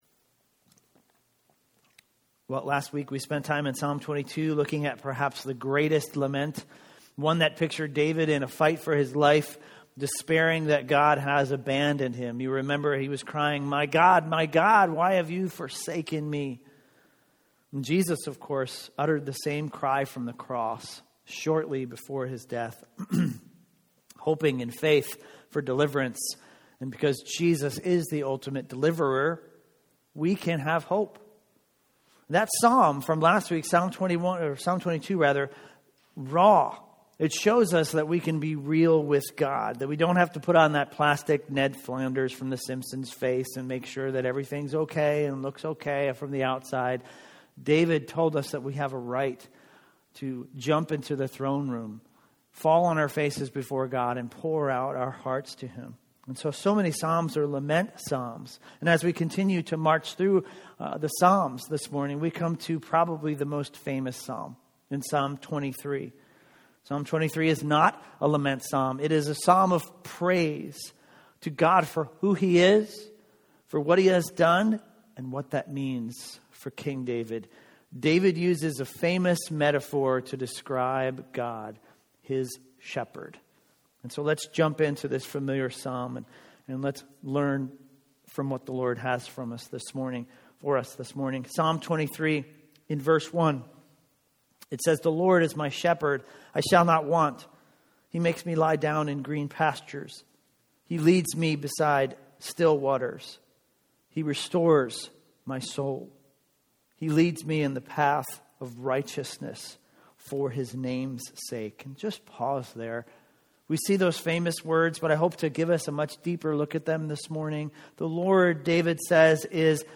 A message from the series "Joshua." In Joshua 20 we learn that God not only cares about justice, he provides justice.